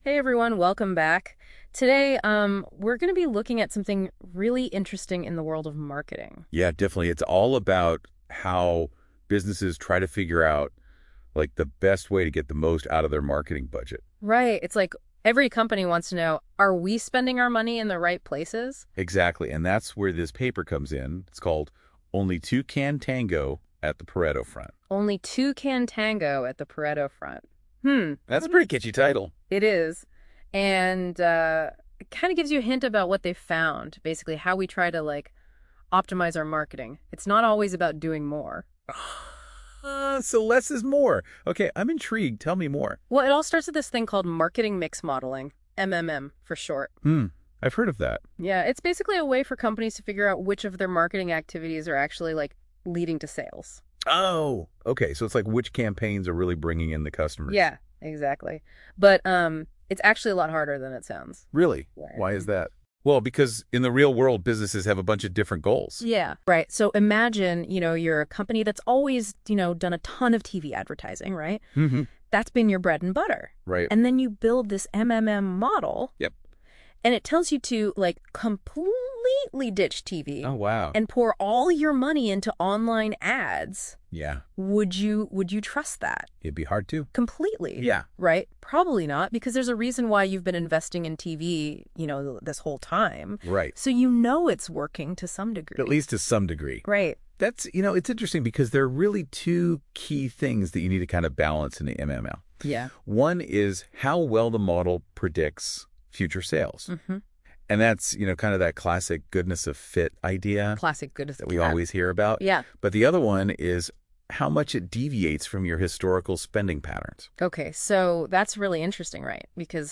Note : This Podcast is generated through Notebook LM.